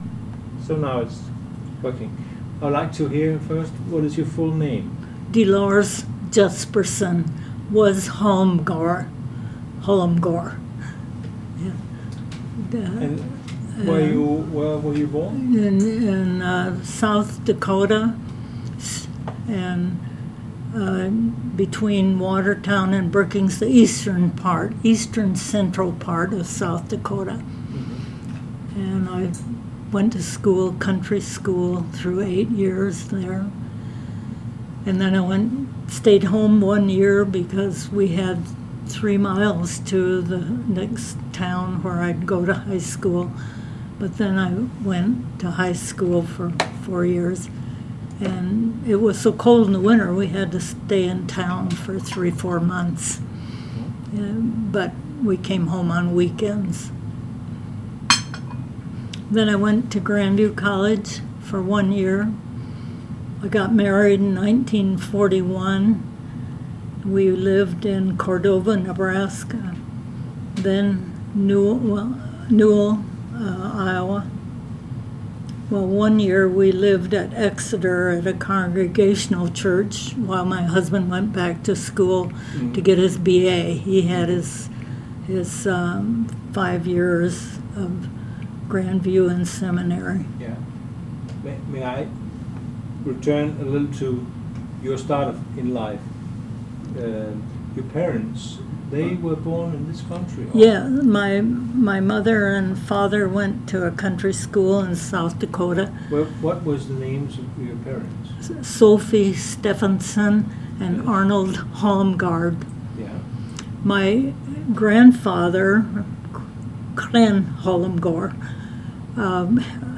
Audio recording of the interview